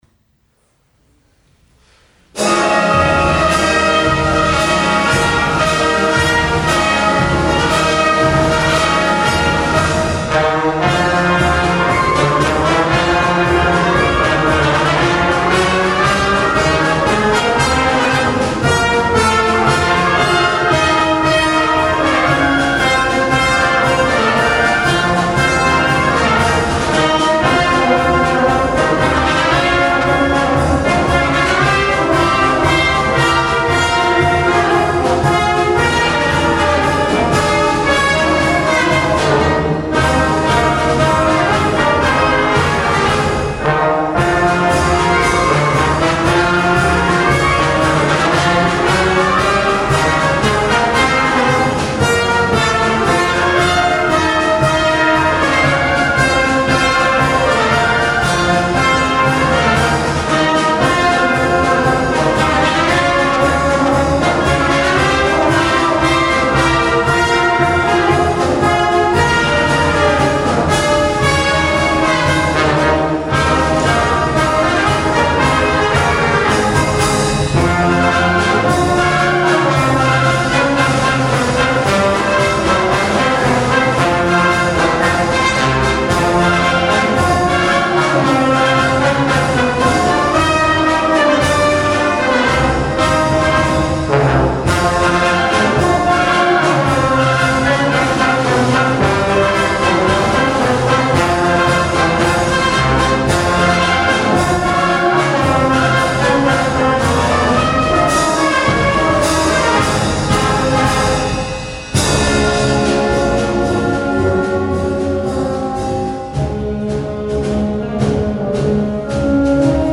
Jahreskonzert 2019